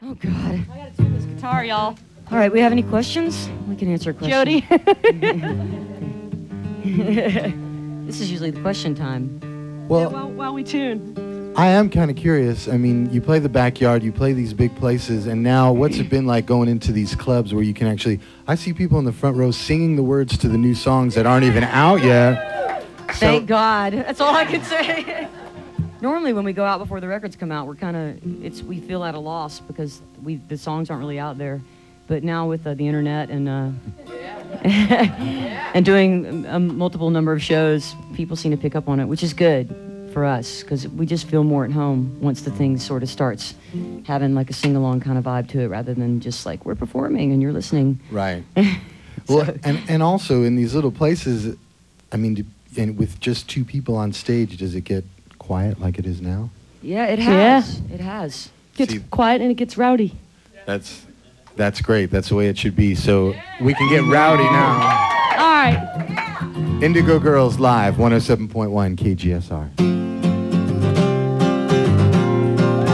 (acoustic duo show)
05. interview (1:12)